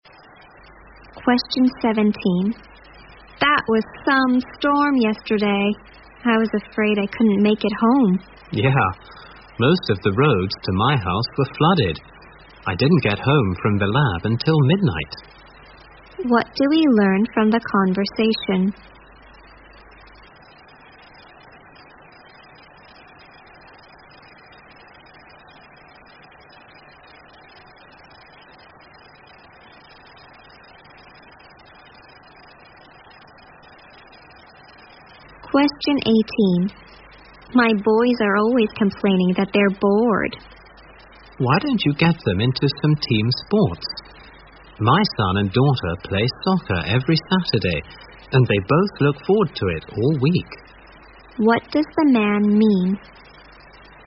在线英语听力室193的听力文件下载,英语四级听力-短对话-在线英语听力室